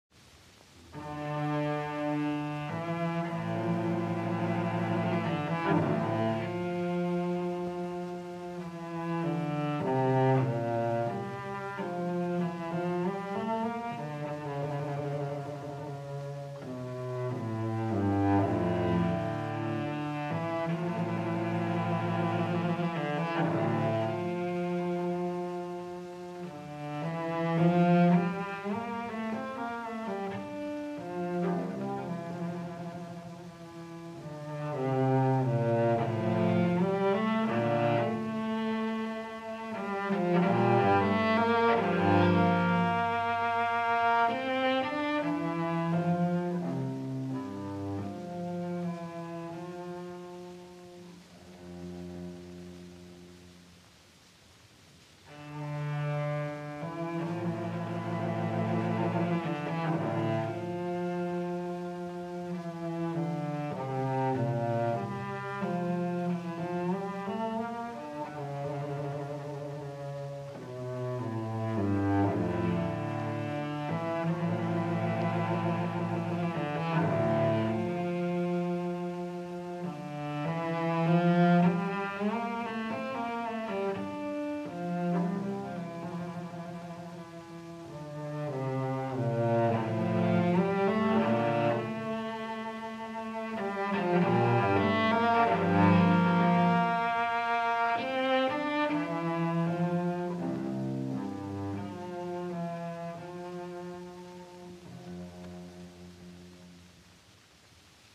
* Johann Sebastian Bach – Sarabande from Cello Suite No.2 in D minor